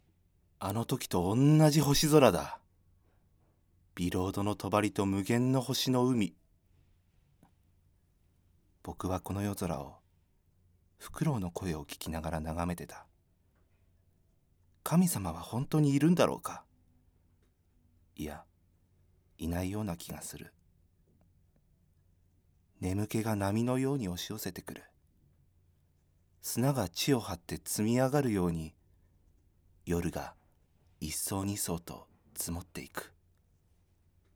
ファンタジー
ボイスサンプル